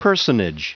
Prononciation du mot personage en anglais (fichier audio)
Prononciation du mot : personage